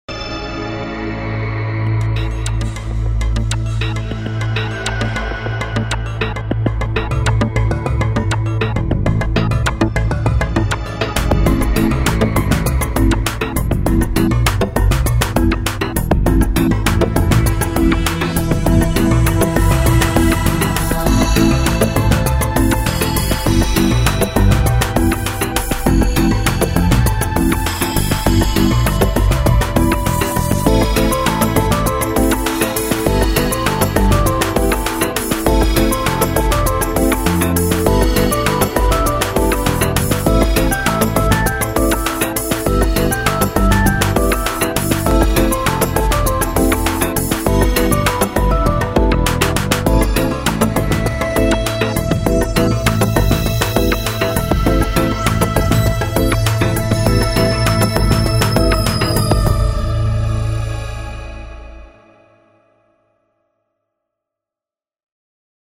Multisample-based, fully-featured synthesizer engine